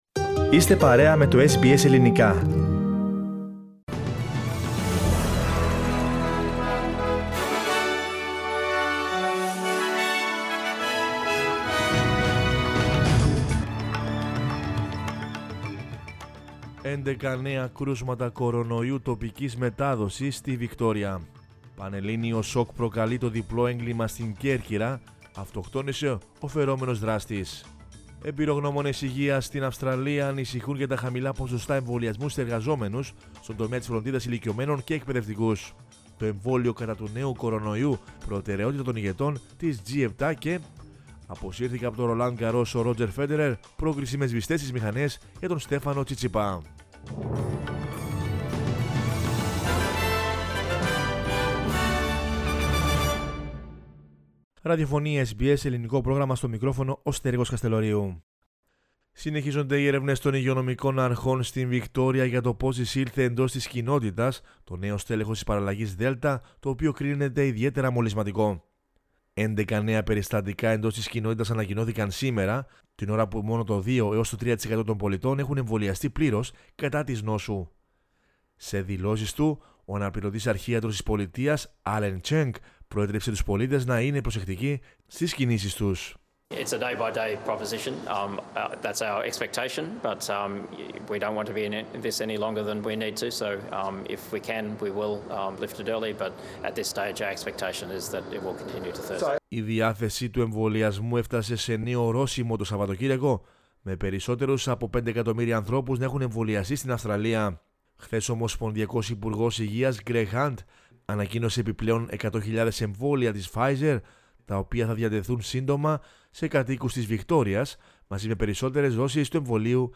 News in Greek from Australia, Greece, Cyprus and the world is the news bulletin of Monday 7 June 2021.